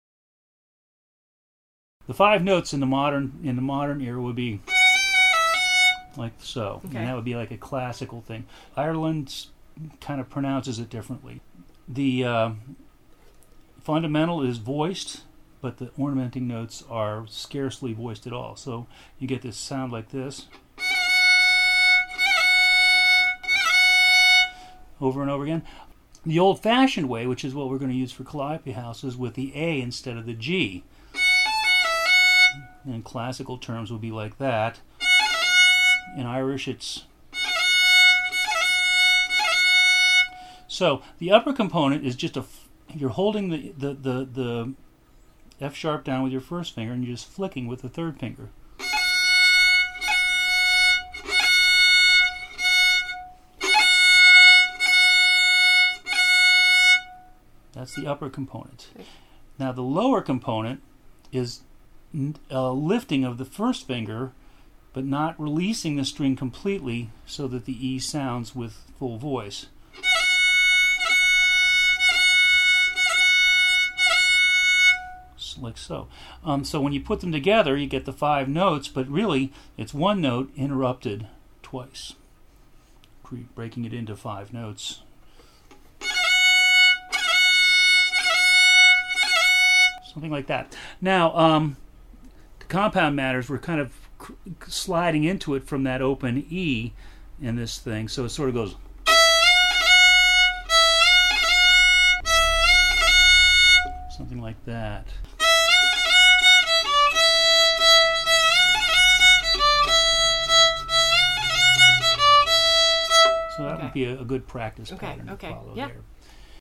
Description: The "Big" Sligo ornament. It'll take some woodshedding to get a light enough touch to throw this expressive staple ornament properly.